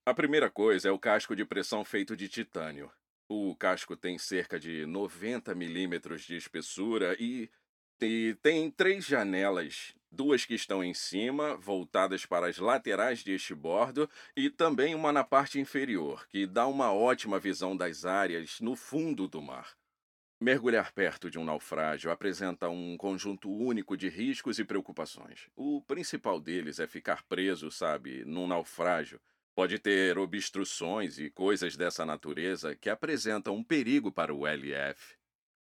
Portekizce Seslendirme
Erkek Ses